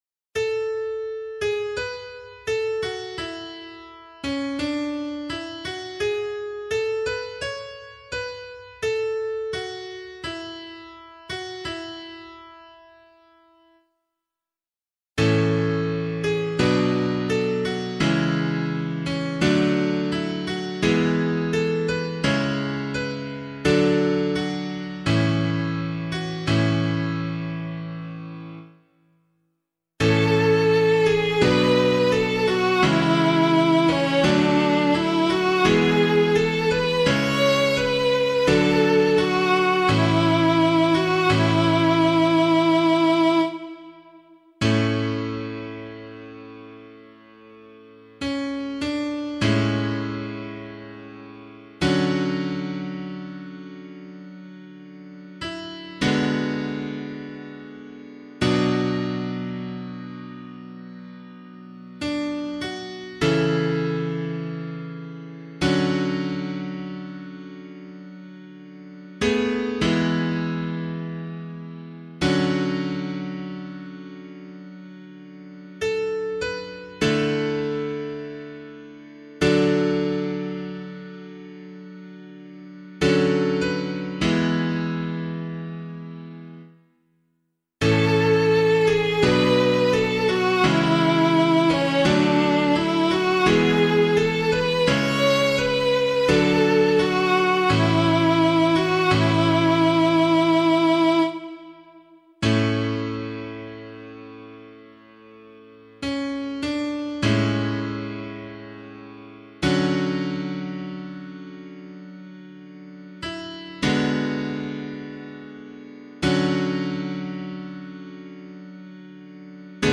003 Advent 3 Psalm C [APC - LiturgyShare + Meinrad 8] - piano.mp3